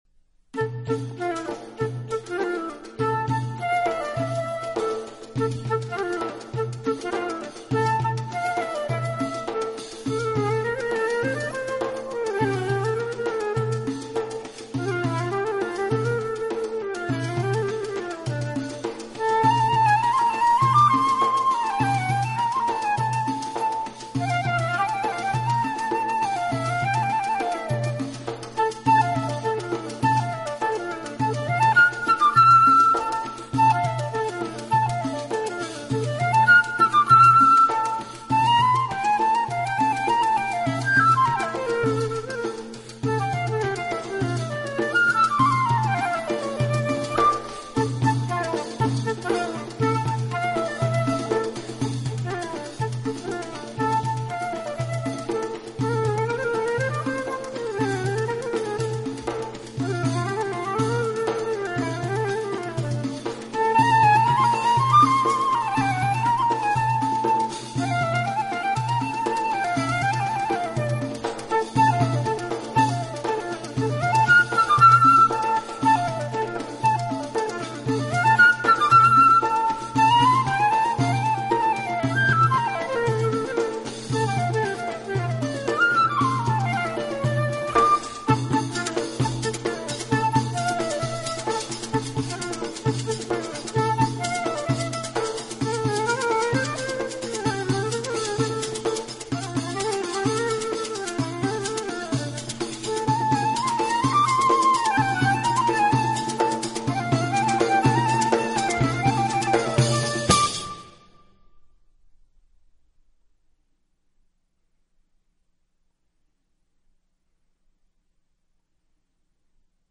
音乐类型：新世纪